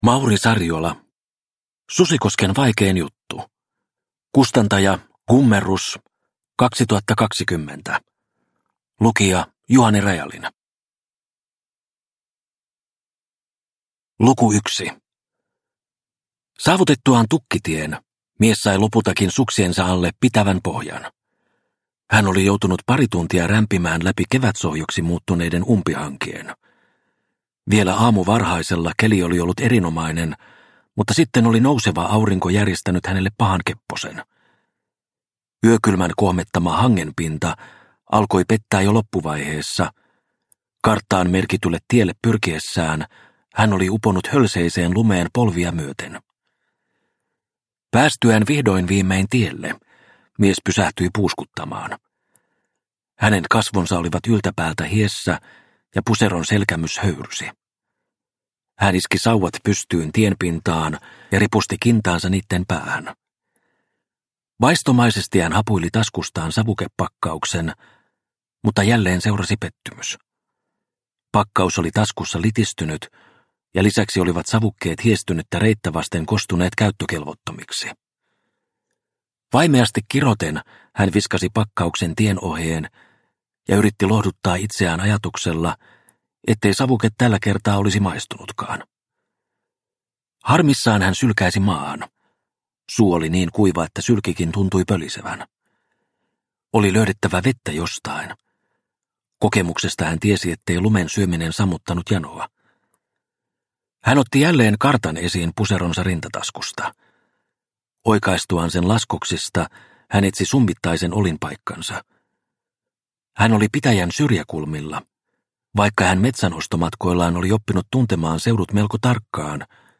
Susikosken vaikein juttu – Ljudbok – Laddas ner